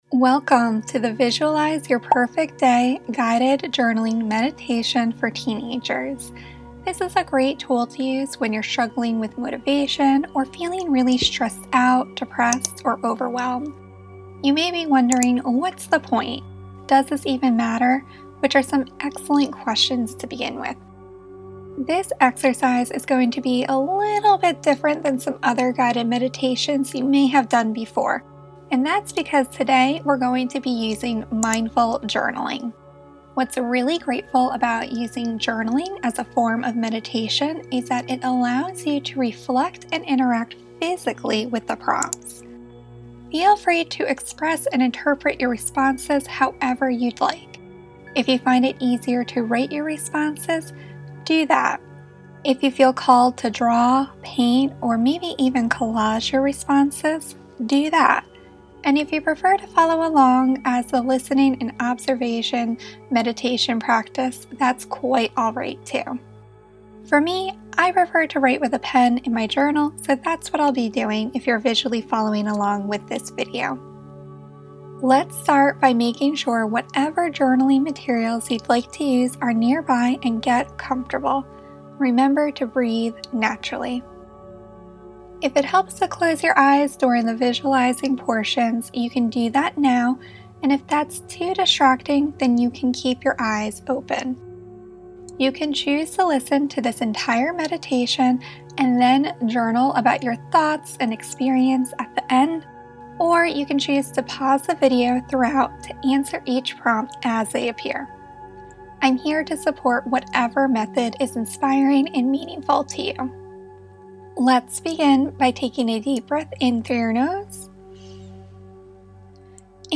Visualize Your Perfect Day Guided Journaling Meditation